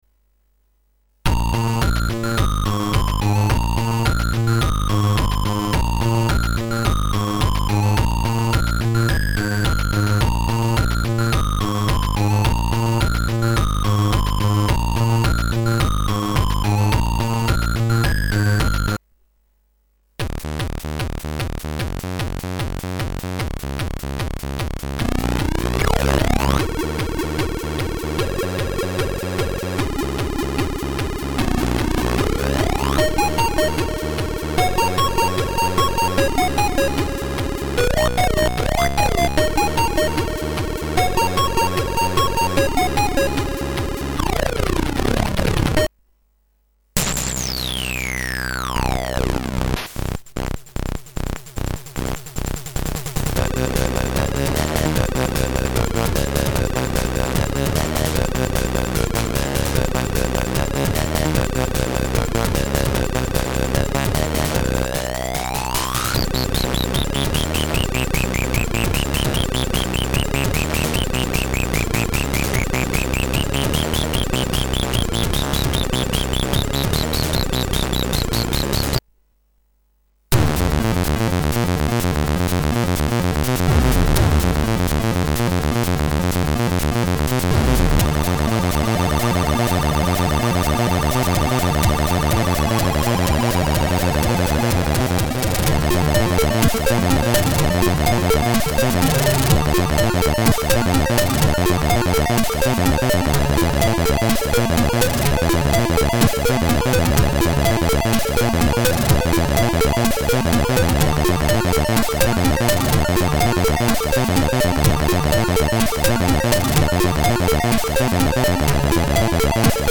Emulator sound example v24.7
AYEmulDemoMusic.mp3